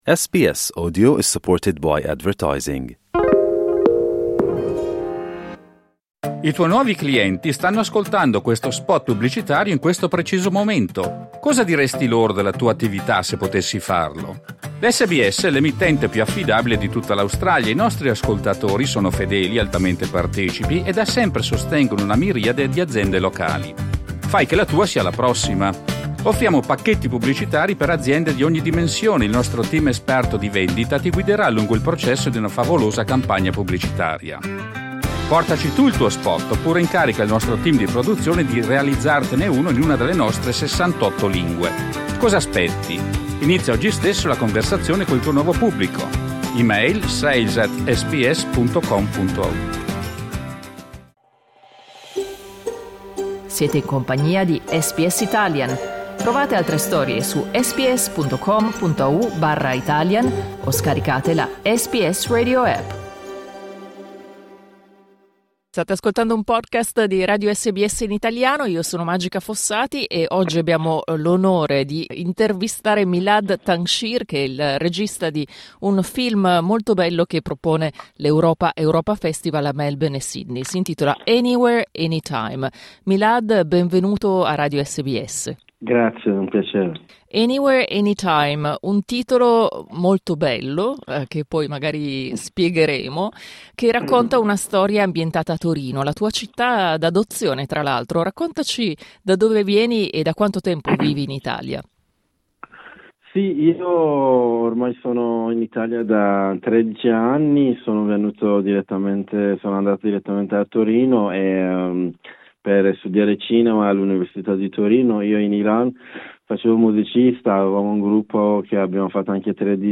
… continue reading 1 Giornale radio giovedì 27 febbraio 2025 11:58 Play Pause 2h ago 11:58 Play Pause बाद में चलाएं बाद में चलाएं सूचियाँ पसंद पसंद 11:58 Il notiziario di SBS in italiano.